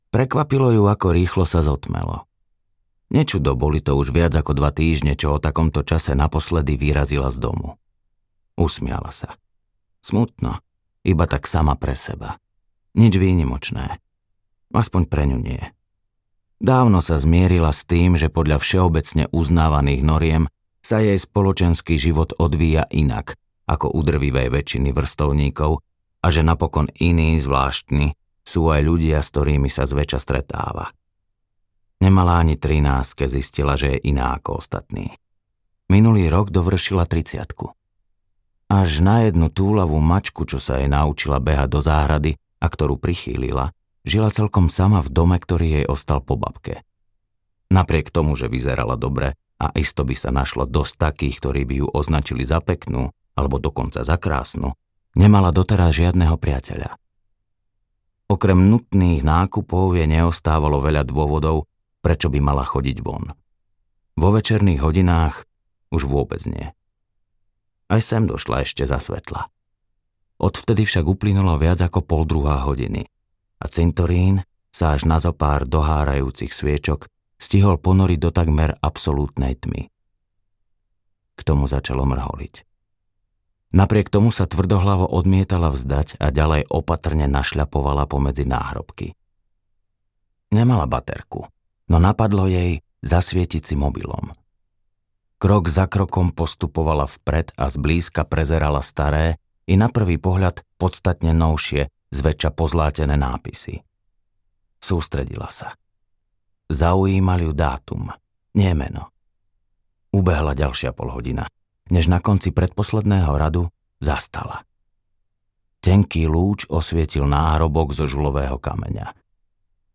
Medzi nebom a zemou audiokniha
Ukázka z knihy